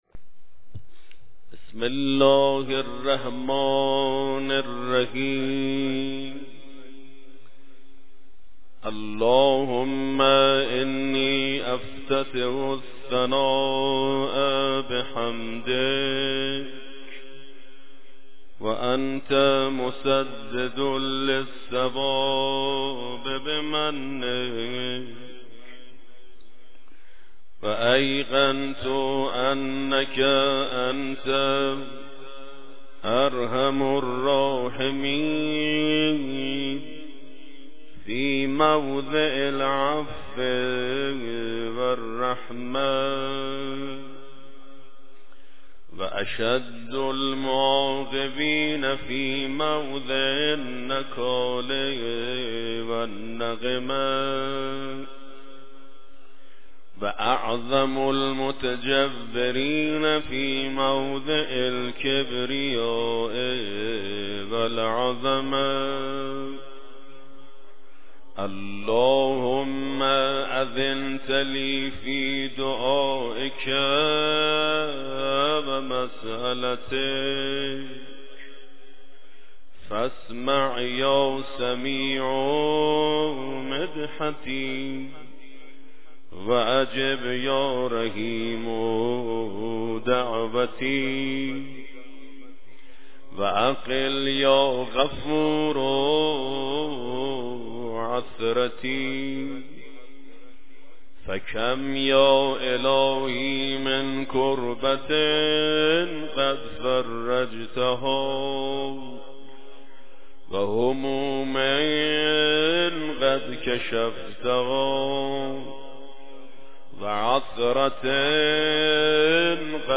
قرائت دعای افتتاح